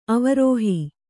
♪ avarōhi